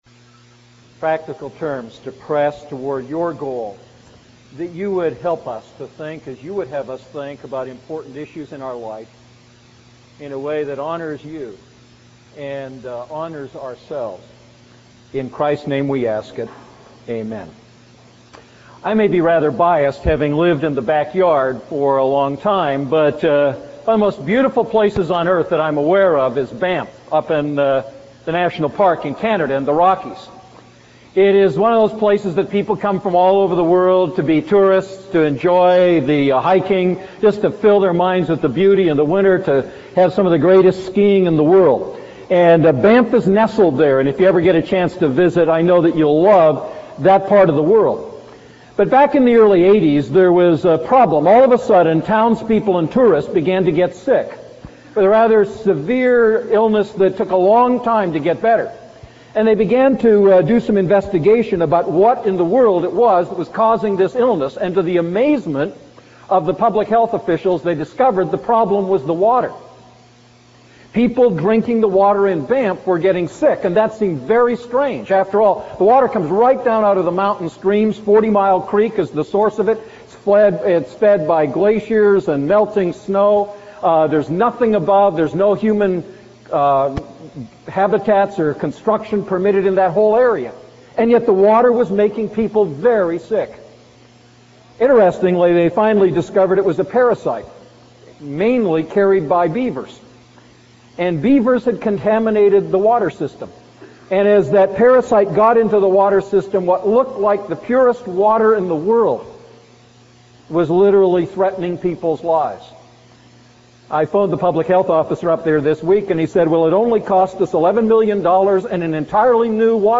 A message from the series "1 Thessalonians."